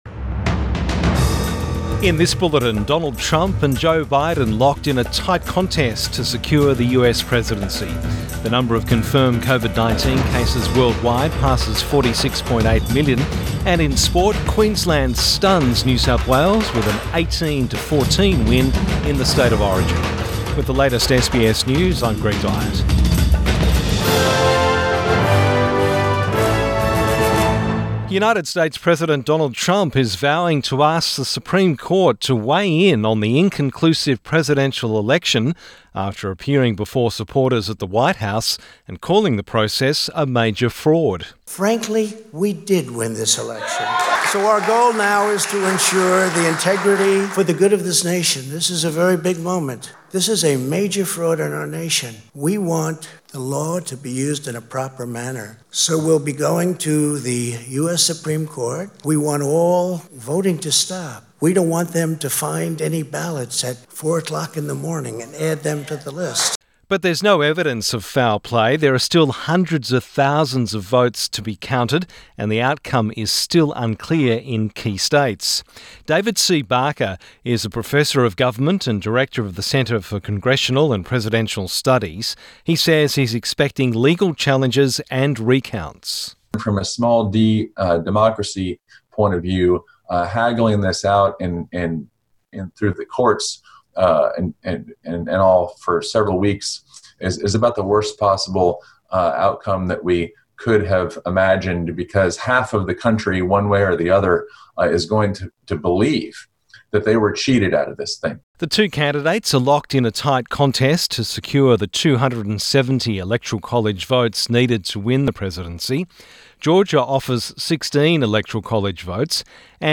AM bulletin 5 November 2020